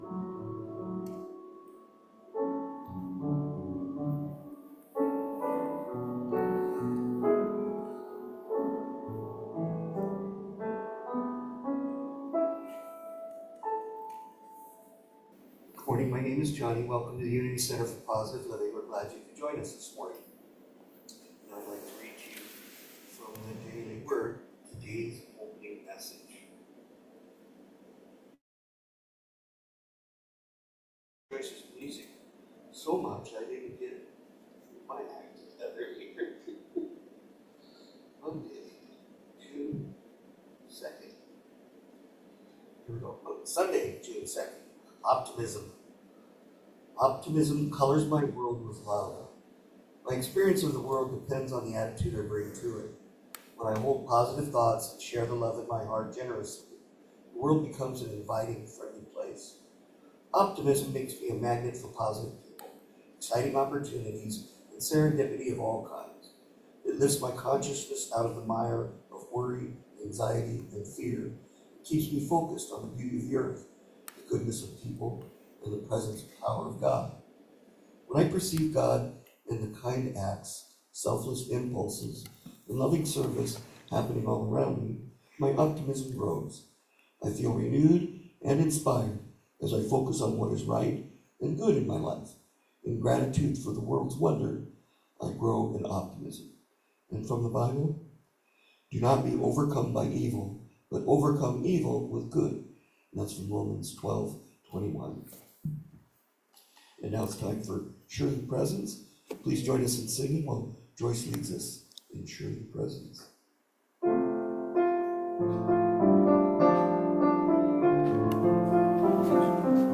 June 2, 2024 Service